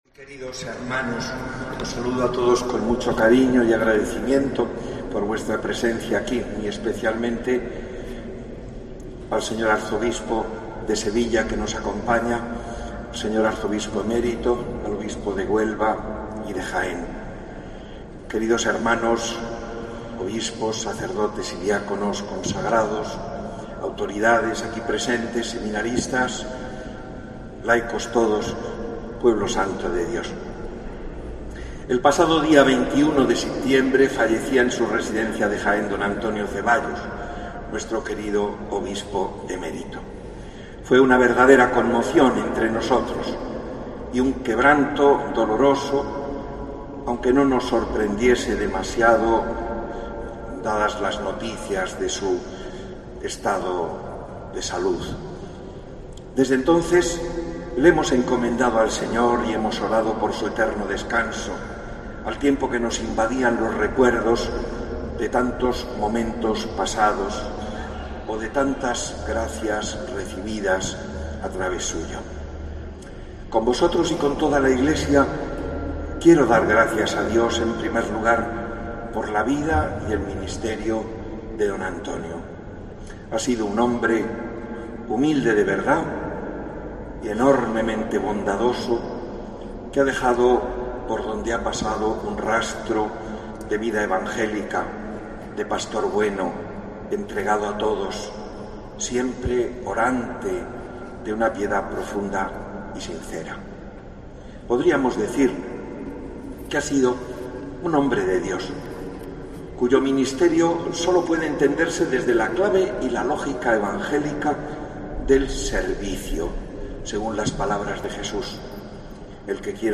Con posterioridad, a las 12 del mediodía, daba comienzo el funeral en la Catedral presidido por el Obispo de la Diócesis, D. Radael Zornoza, y en cuya homilía, tenía palabras de recuerdo para quien hasta 2011, casi 20 años fue el prelado de Cádiz y Ceuta, un hombre bueno y que siempre se volcó de manera especial con los más desfavorecido, tanto que le llevó a crear la red de atención a los inmigrantes, Tierra de Todos.
Homilía, Obispo de Cádiz D. Rafael Zornoza